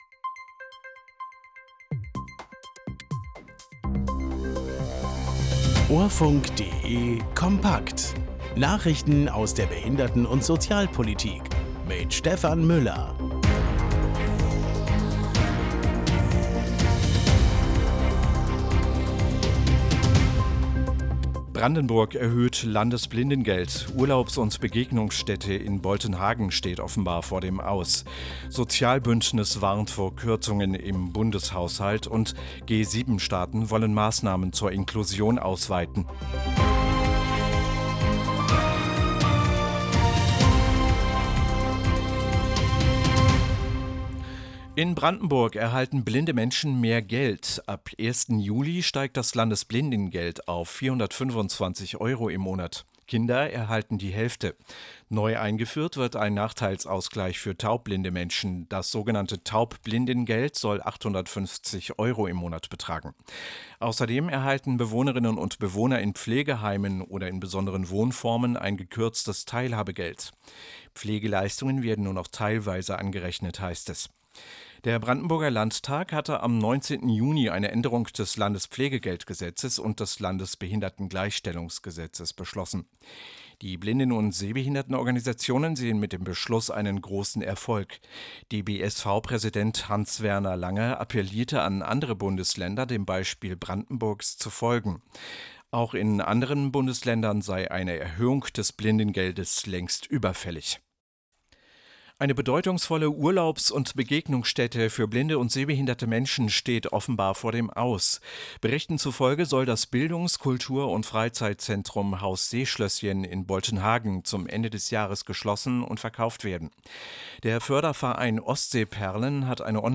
Nachrichten aus der Behinderten- und Sozialpolitik vom 26.06.2024